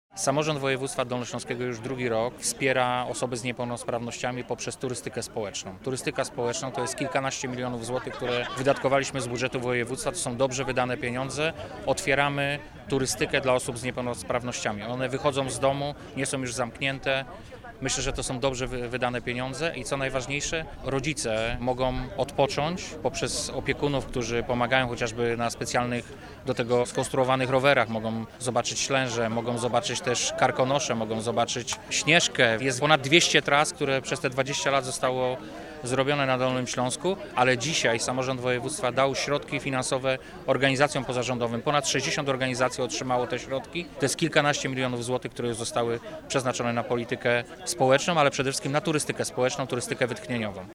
Kampania pod nazwą „Dolny Śląsk bez barier – 200 tras turystycznych dla osób z niepełnosprawnościami – 20 lat przemian i budowania dostępności” podsumowuje ostatnie lata i szerokie spektrum działalności Dolnego Śląska wobec osób z niepełnosprawnościami. Mówi Wojciech Bochnak, wicemarszałek Województwa Dolnośląskiego.